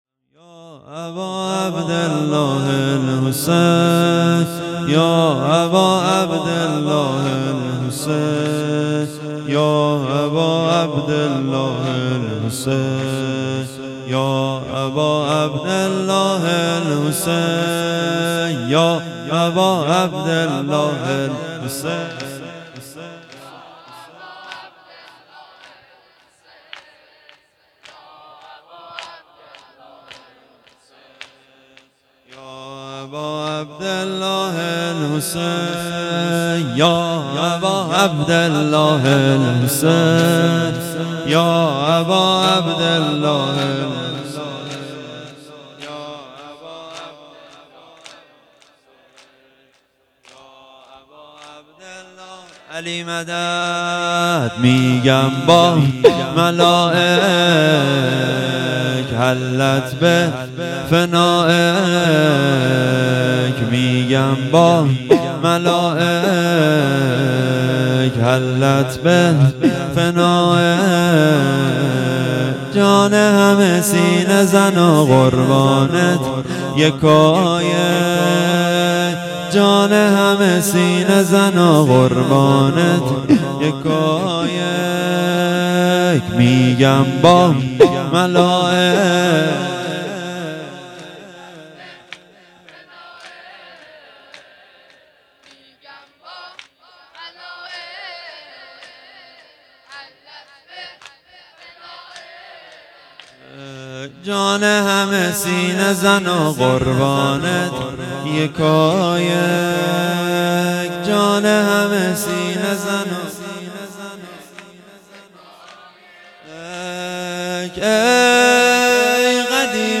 شب پنجم محرم ۱۴۴۴
محرم الحرام ١٤٤٤